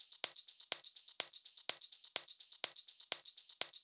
Live Percussion A 20.wav